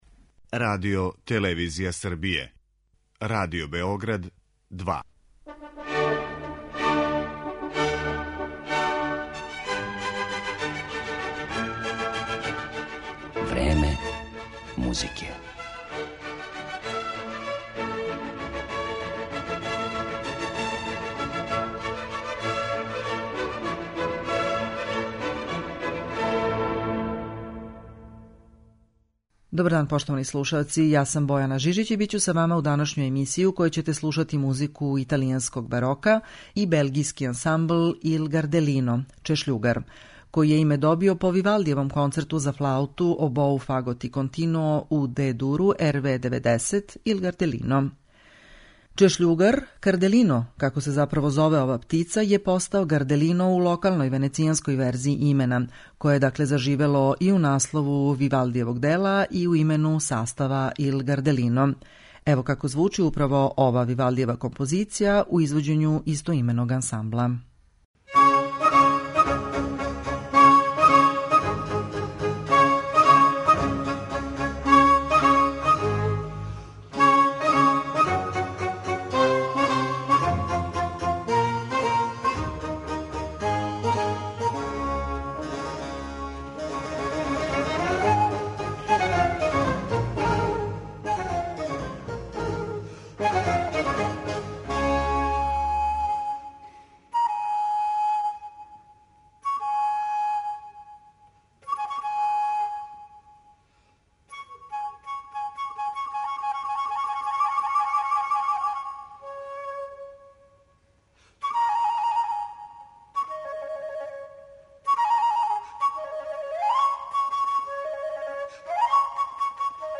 По Вивалдијевом концерту за флауту, обоу, фагот и континуо у Дe-дуру, Il Gardellino (Чешљугар), добио је име изврсни белгијски ансамбл за рану музику, који на веома вешт начин мири историјску веродостојност и жељу да звучи модерно.